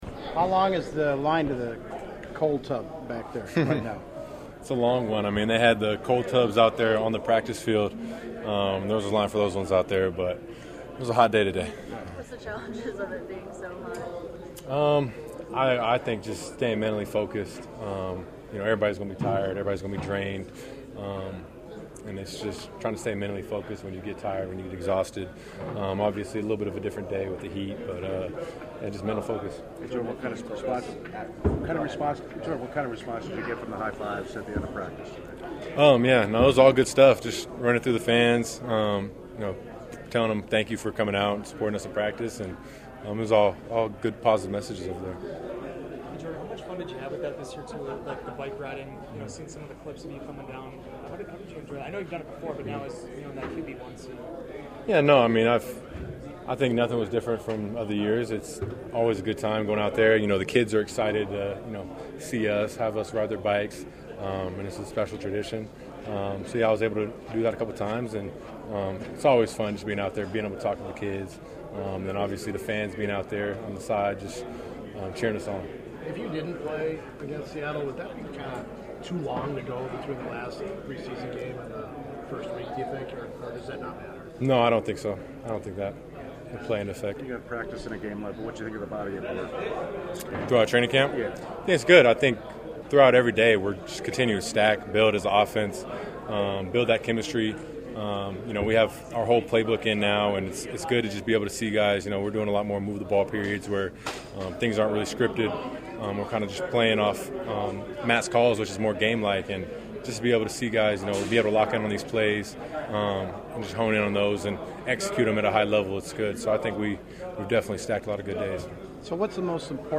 In the locker room, Love gathered reporters around his locker to talk about the hot day of work, the five weeks of practice and the state of the offense as training camp is about to close.